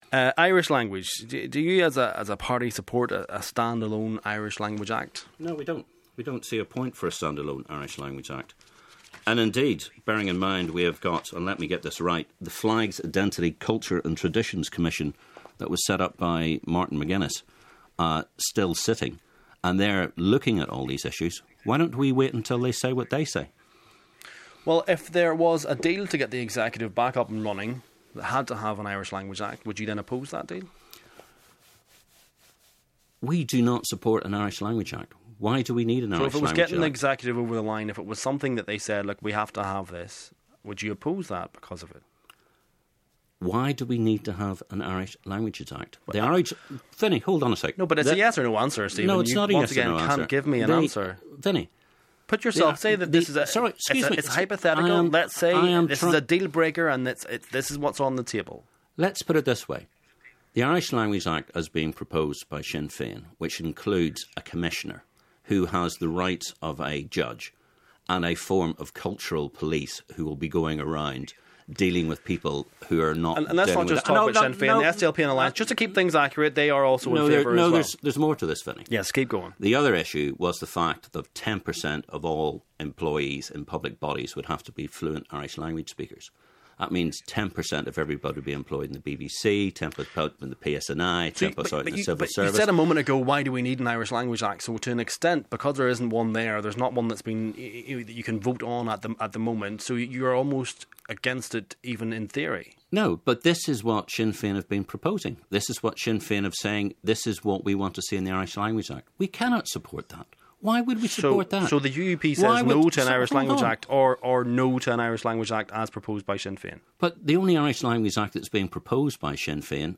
UUP’s Steve Aiken quizzed on party’s Irish language act stance